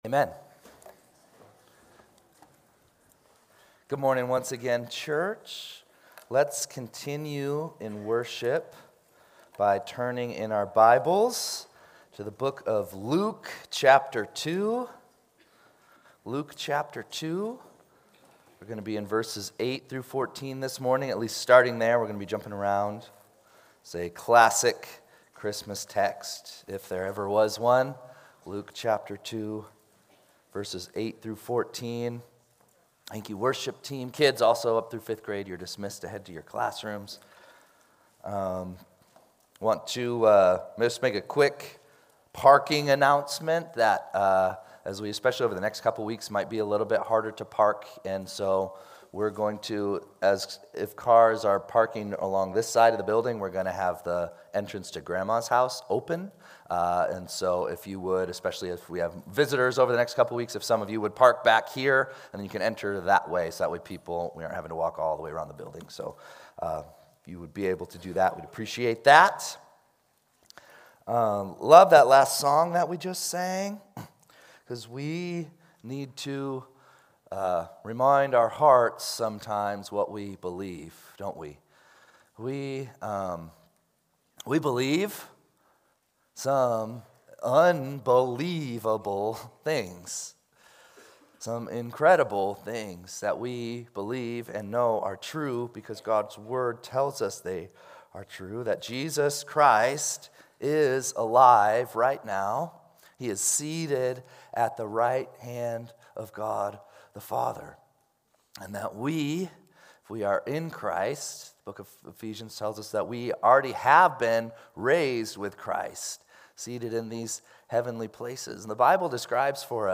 12-7-25-Sunday-Service.mp3